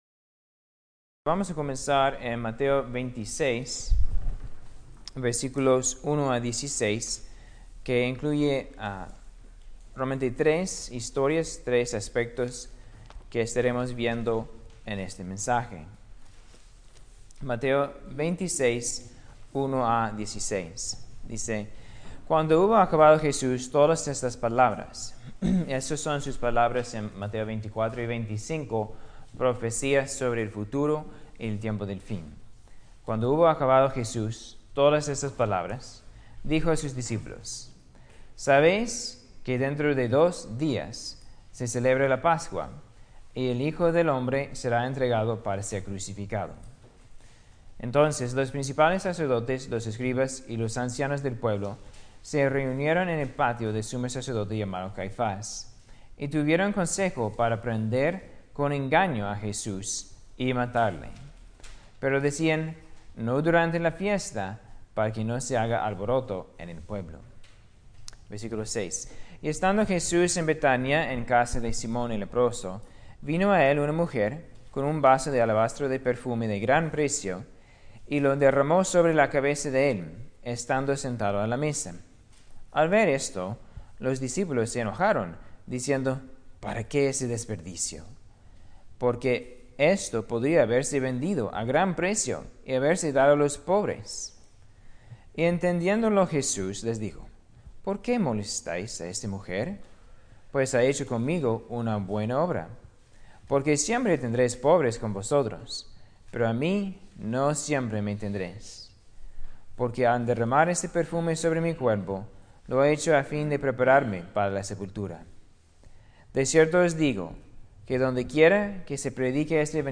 Conferencia Bíblica VII.9 - La conspiración en contra de Jesús - Iglesia Cristiana Biblica Monte Moriah